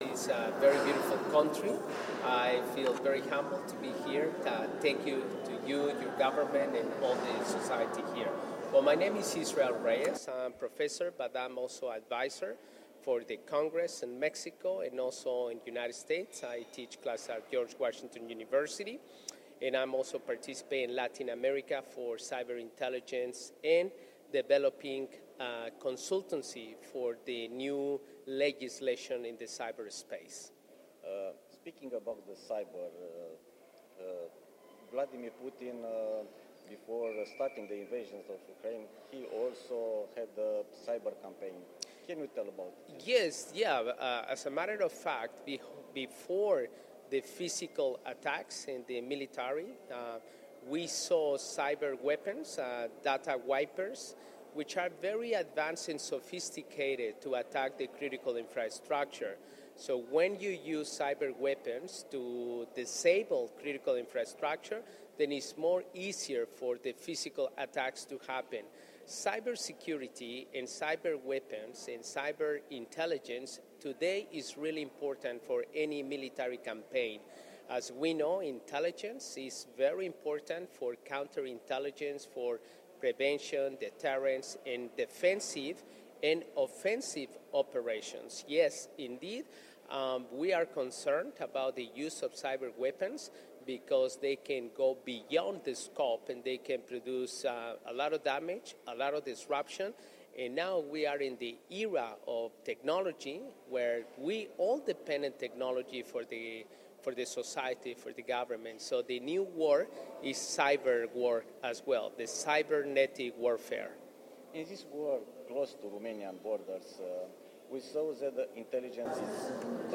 unde a oferit un interviu pentru Monitorul Apărării şi Securităţii